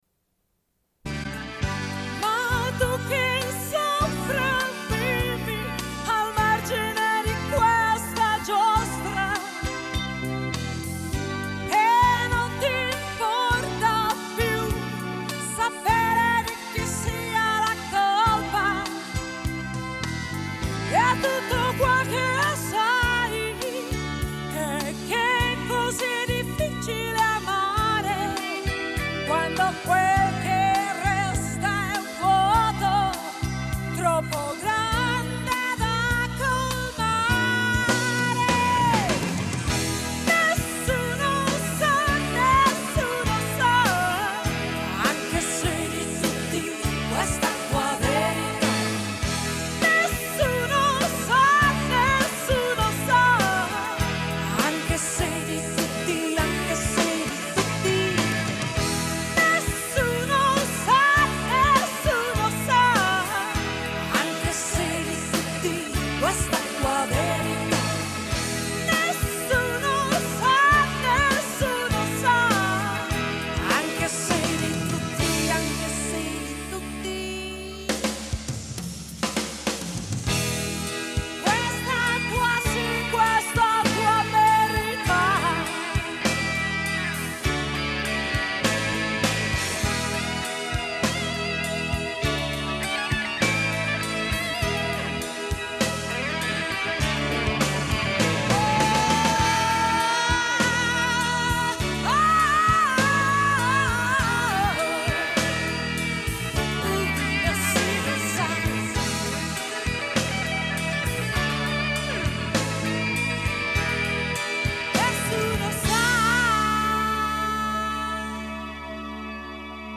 Une cassette audio, face B29:21